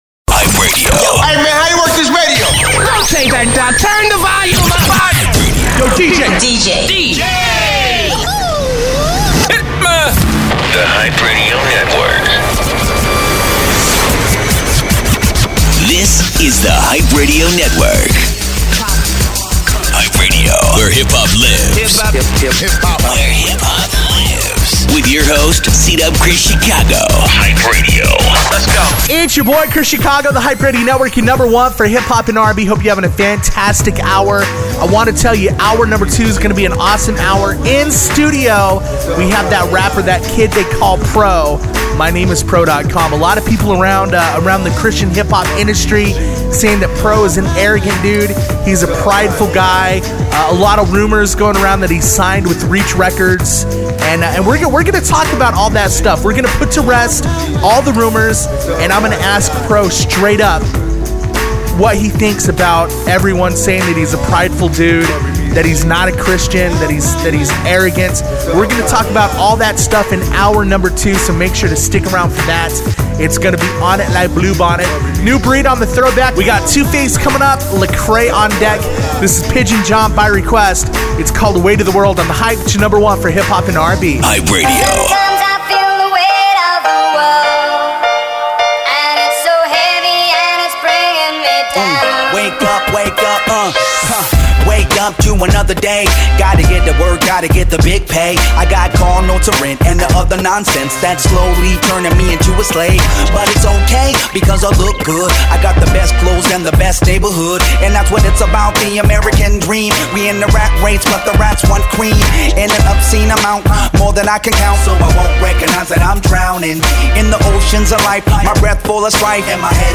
The Hype Radio Network is Christian radios #1 syndicated hip hop show.